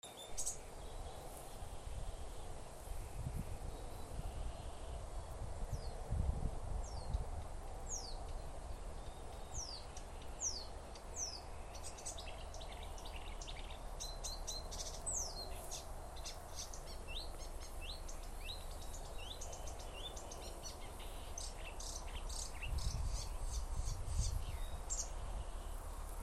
Marsh Warbler, Acrocephalus palustris
Administratīvā teritorijaJūrmala
StatusSinging male in breeding season